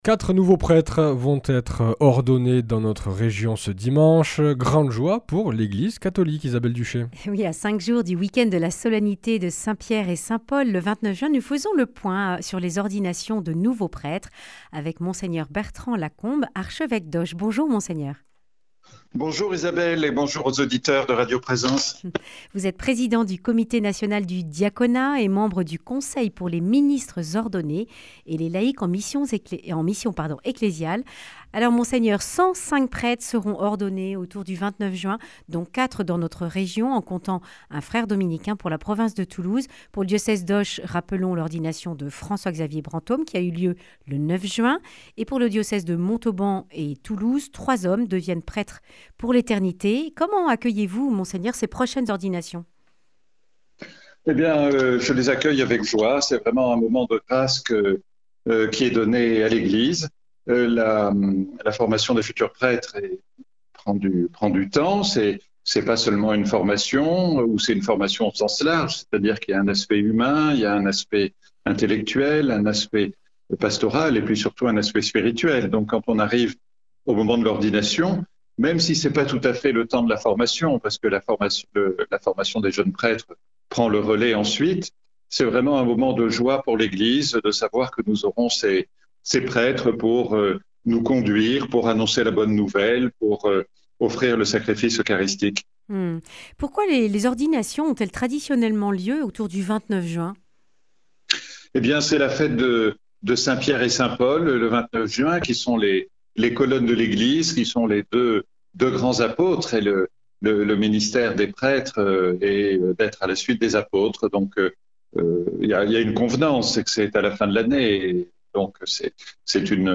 Accueil \ Emissions \ Information \ Régionale \ Le grand entretien \ Quelle mission pour les prêtres ordonnés en 2024 ?